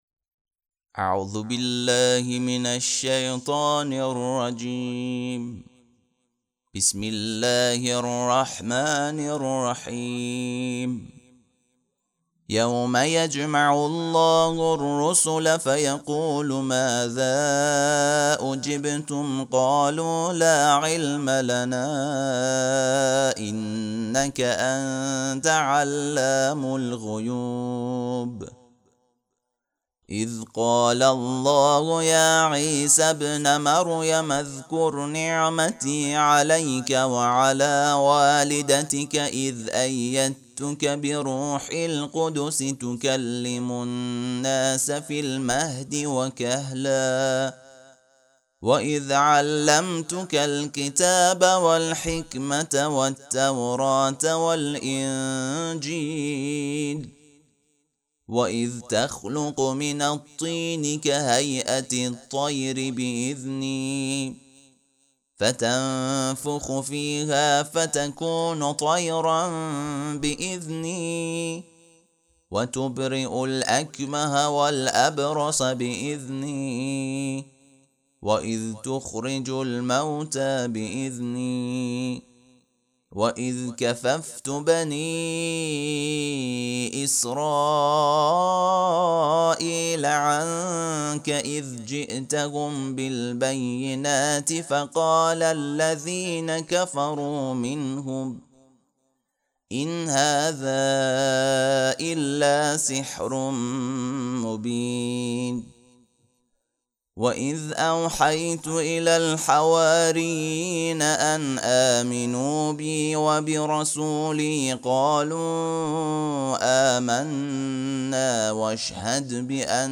ترتیل صفحه ۱۲۶ سوره مبارکه مائده(جزء هفتم)
ترتیل سوره(مائده)